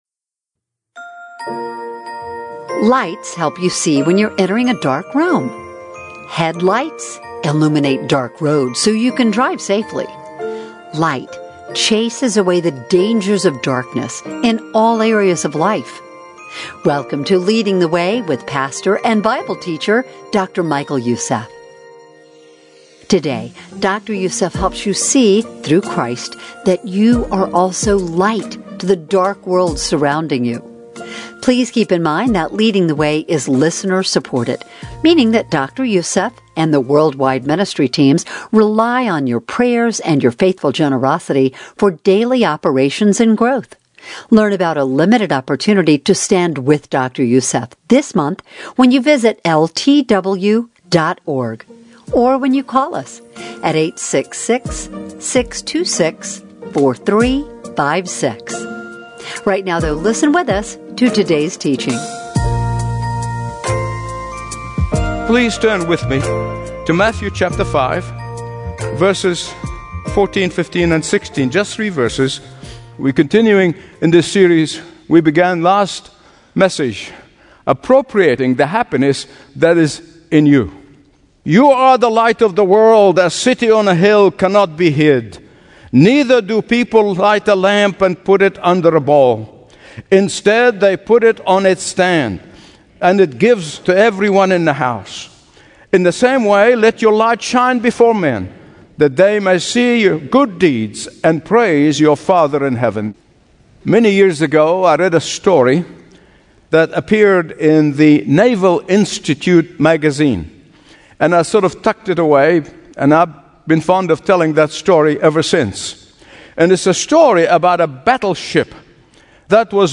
Stream Expository Bible Teaching & Understand the Bible Like Never Before.